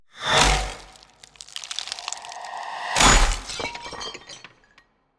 shared_ice_freeze.wav